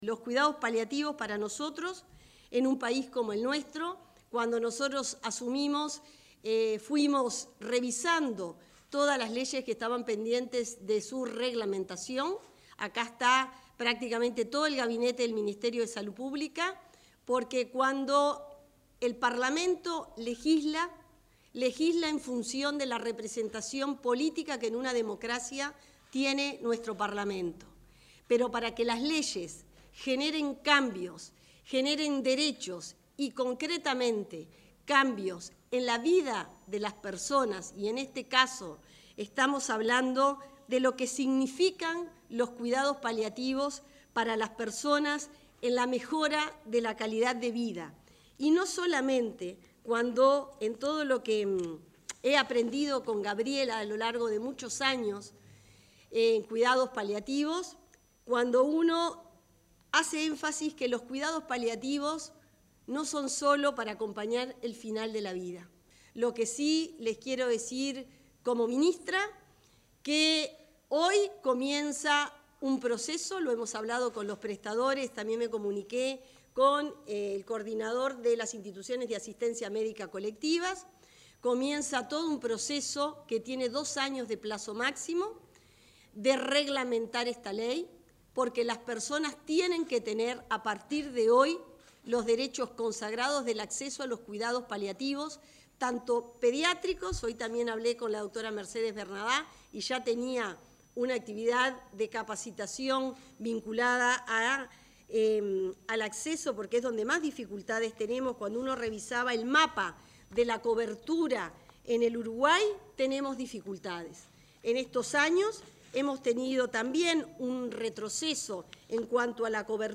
Declaraciones de autoridades del Ministerio de Salud Pública